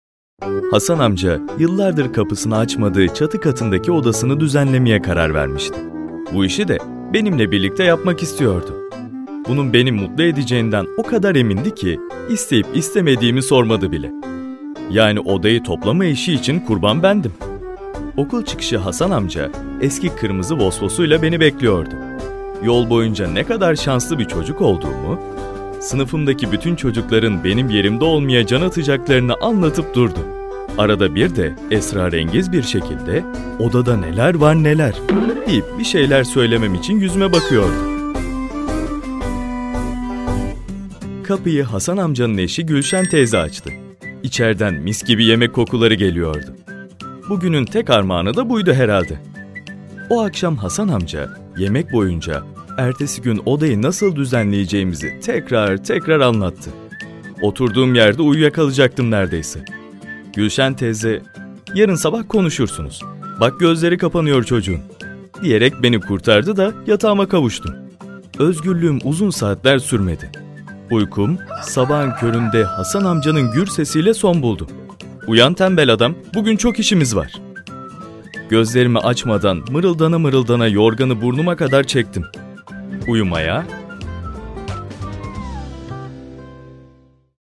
Book Voice Over
Making written books aloud by reading them in the studio environmentbook voiceover” called service.